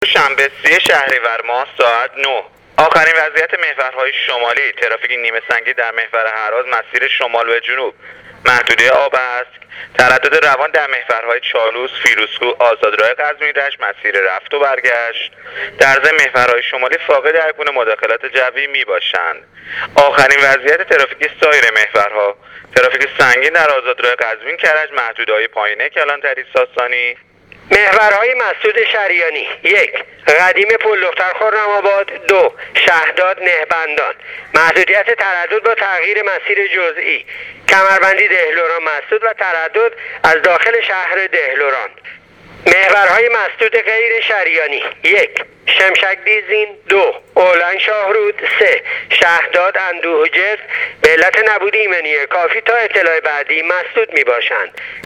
گزارش رادیو اینترنتی پایگاه خبری وزارت راه و شهرسازی از آخرین وضعیت ترافیکی جاده‌های کشور تا ساعت ۹ شنبه ۳۰ شهریور ماه/ ترافیک نیمه سنگین در محور چالوس و ترافیک سنگین در آزادراه قزوین-کرج